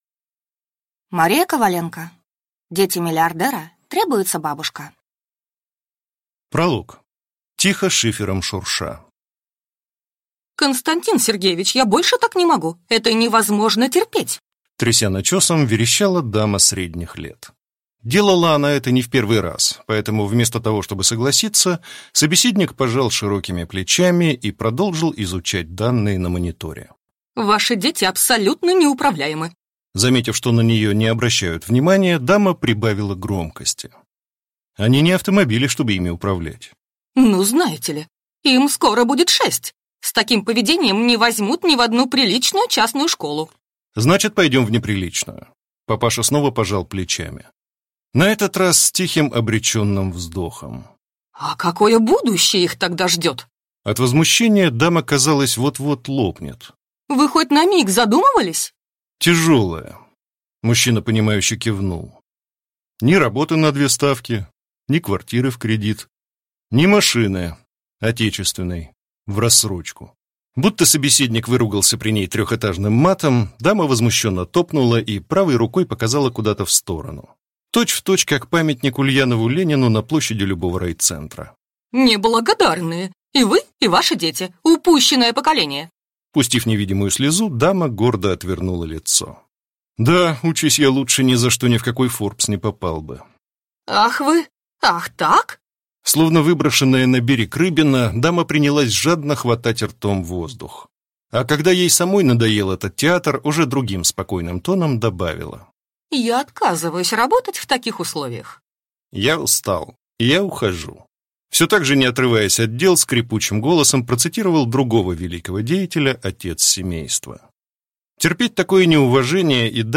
Aудиокнига Дети миллиардера. Требуется бабушка!